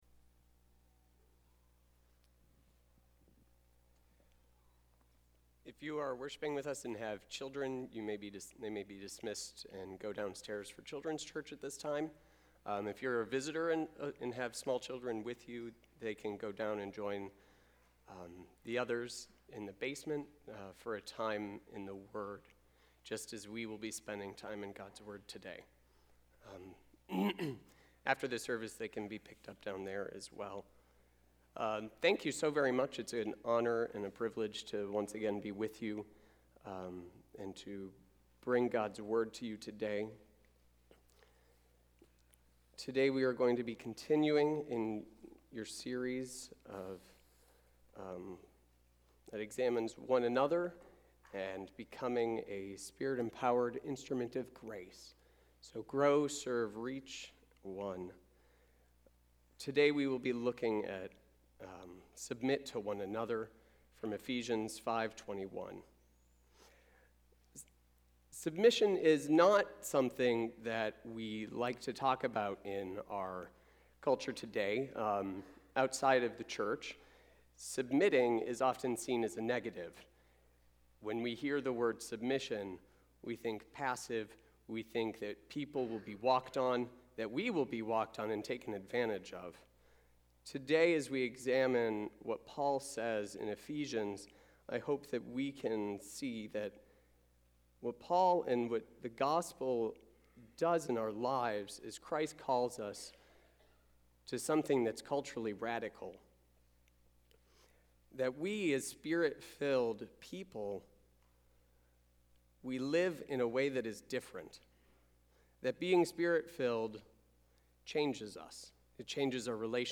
Save Audio Sermon